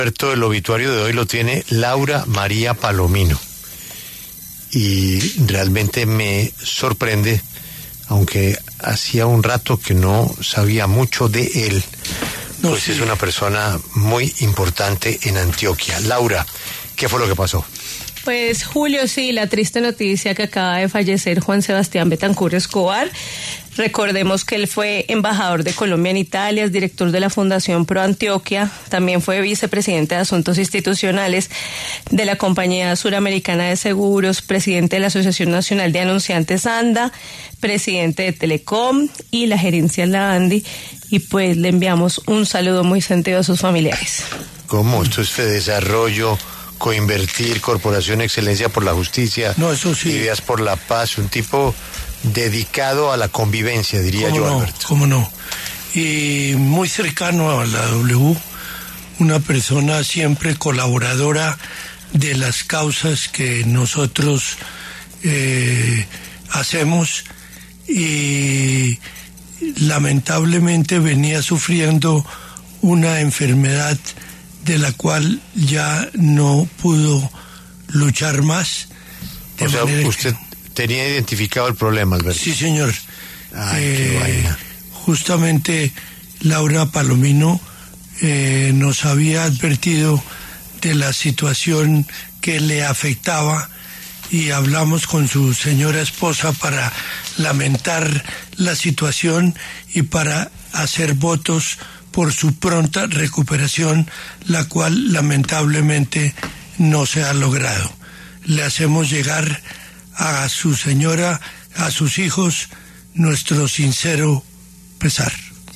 Un día, Juan Sebastián Betancur Escobar habló en los micrófonos de La W. Reviva la entrevista a continuación:
Entrevista de Juan Sebastián Betancur Escobar en La W